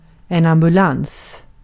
Uttal
Uttal Okänd accent: IPA: /ambɵˈlans/ IPA: /ambɵˈlaŋs/ Ordet hittades på dessa språk: svenska Översättning Substantiv 1. ambulans Andra/okänd 2. cankurtaran Artikel: en .